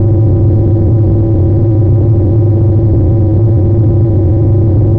spaceEngineLow_001.ogg